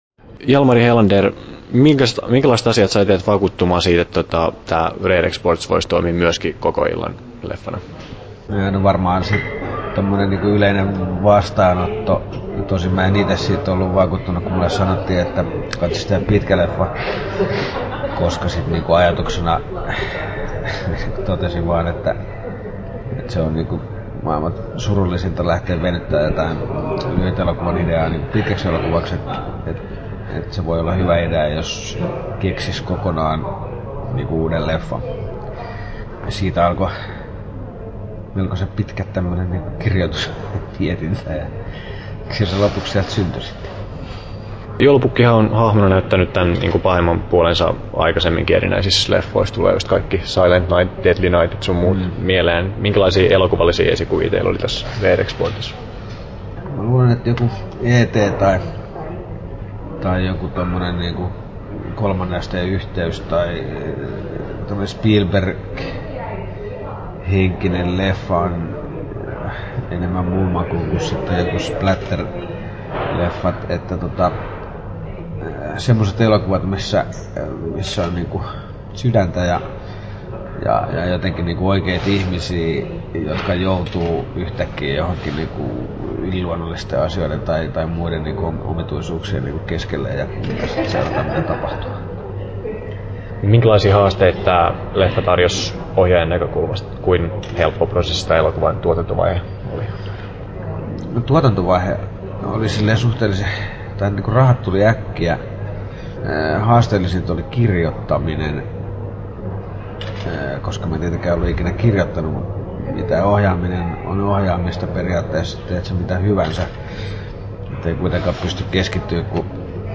Jalmari Helanderin haastattelu Kesto